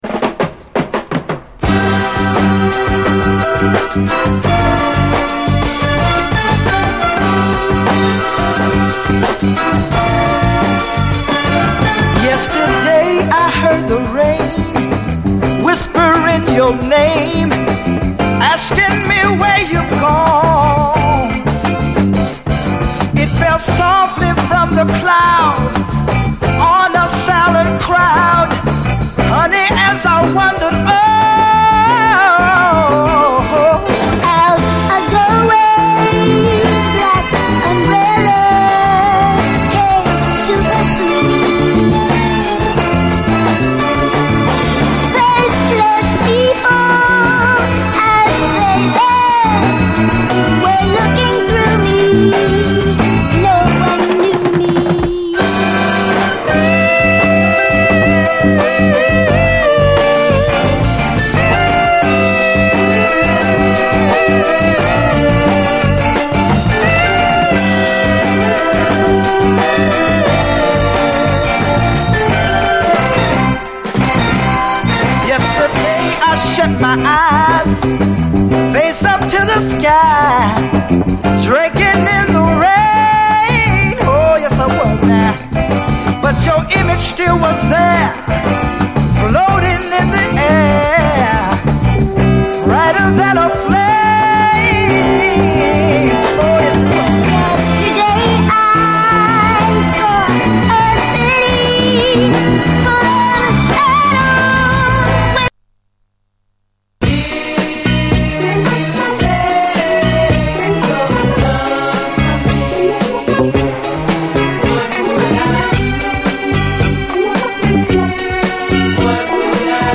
SOUL, 70's～ SOUL
鋭いグルーヴィー・アレンジの傑作クロスオーヴァー・ソウル